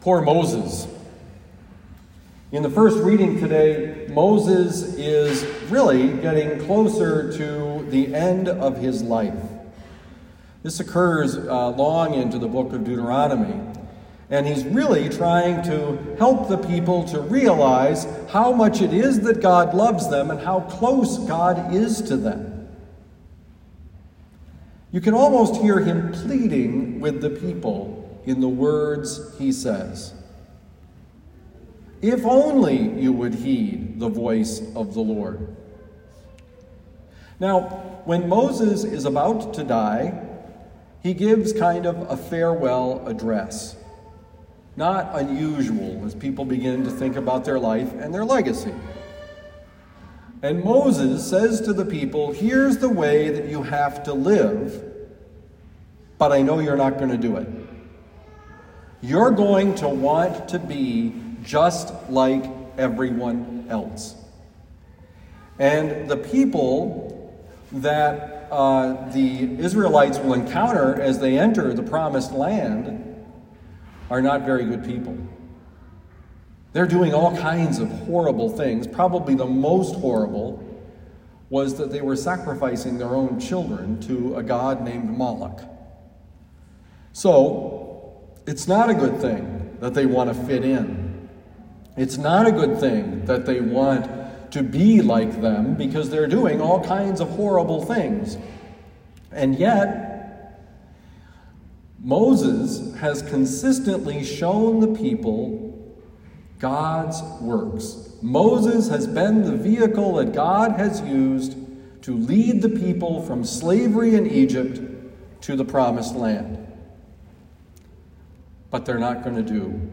Homily given at Our Lady of Lourdes Parish, University City, Missouri.